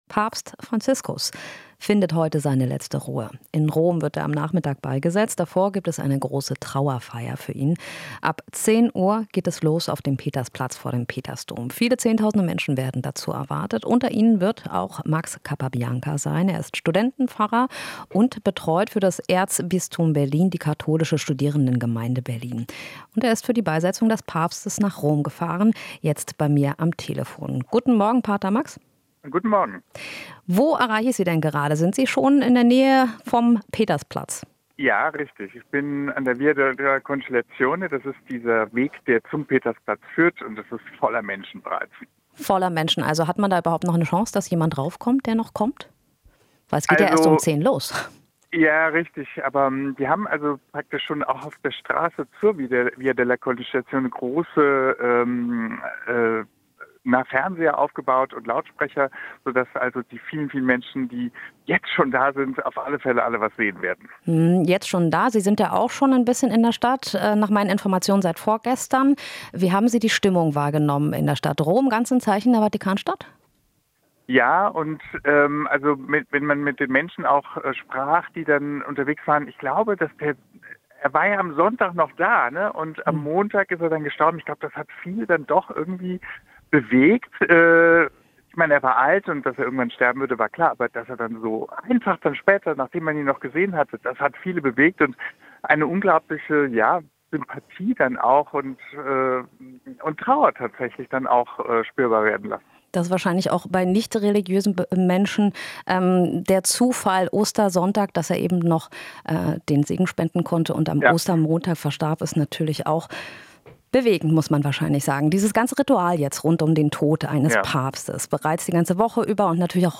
Interview - Trauerfeier für Papst: Berliner Pfarrer sieht viel Dankbarkeit